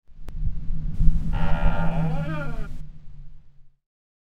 دانلود آهنگ باد 8 از افکت صوتی طبیعت و محیط
دانلود صدای باد 8 از ساعد نیوز با لینک مستقیم و کیفیت بالا
جلوه های صوتی